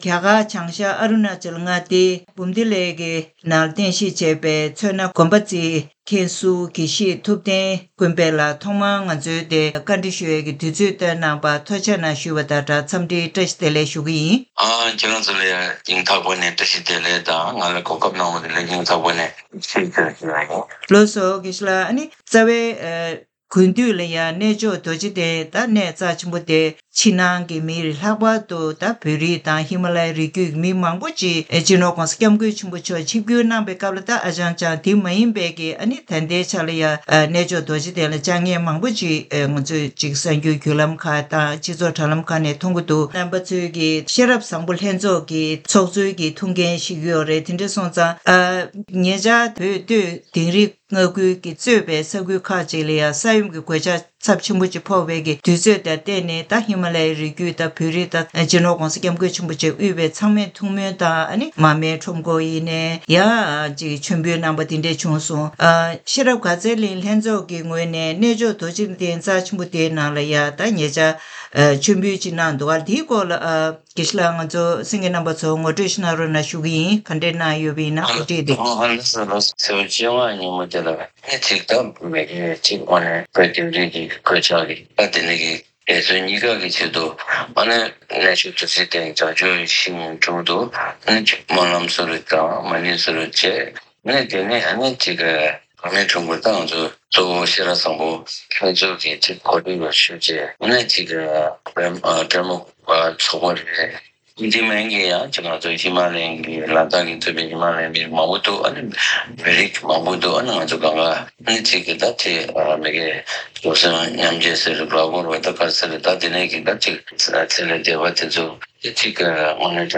གནས་འདྲི་ཞུས་པ་ཞིག་གསན་གནང་གི་རེད།